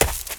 High Quality Footsteps
STEPS Leaves, Run 06, Heavy Stomp.wav